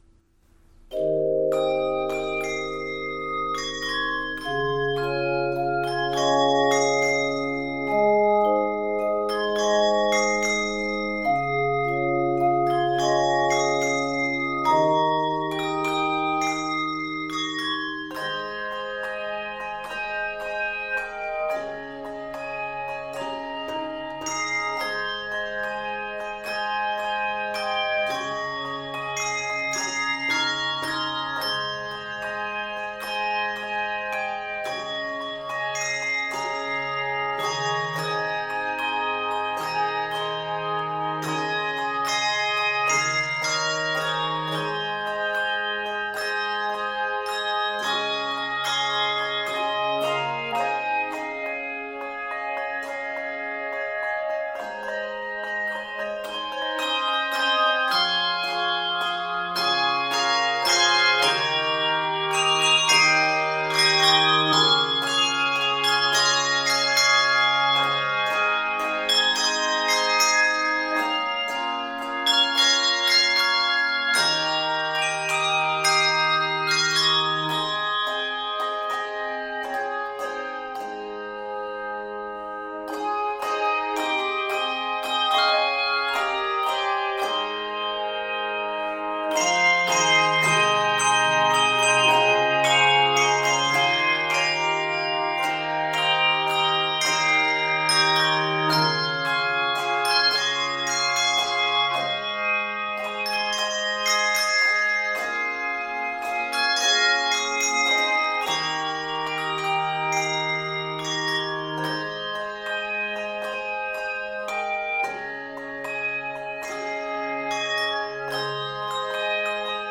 contemporary Christian song
handbells